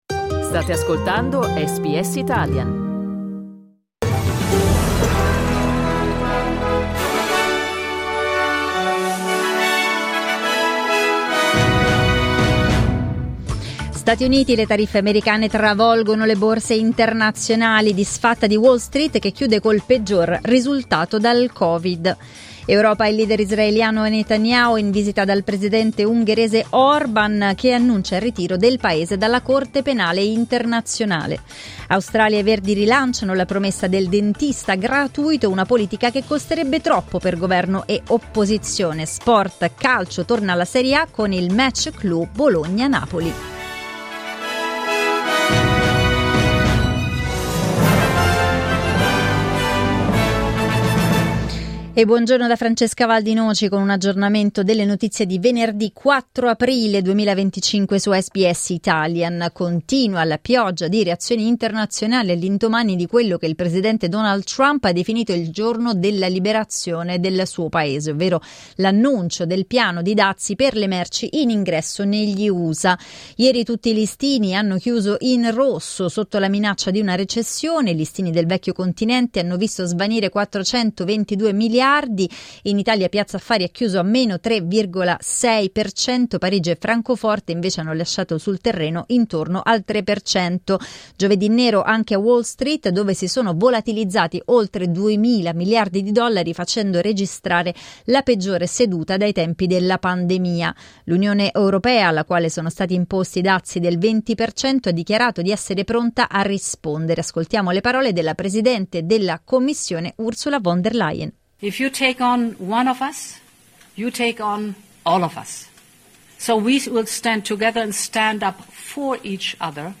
Giornale radio venerdì 4 aprile 2025
Il notiziario di SBS in italiano.